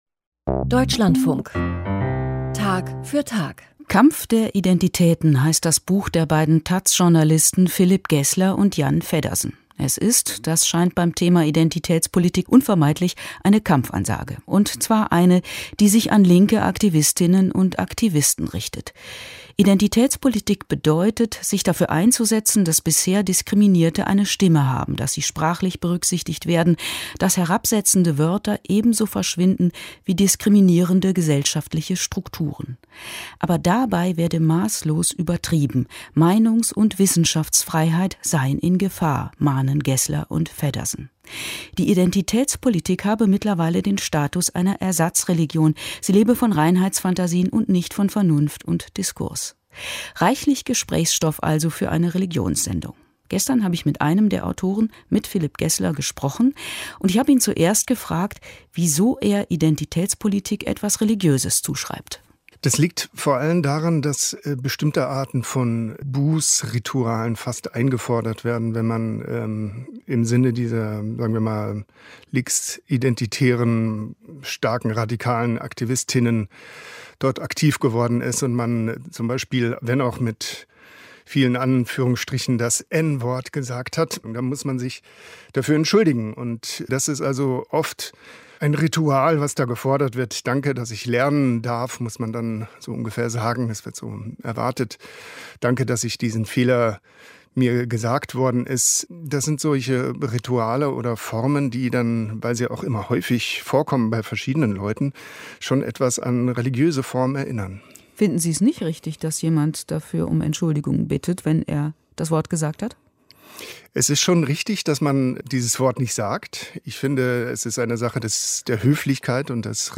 Das Interview ist hilfreich.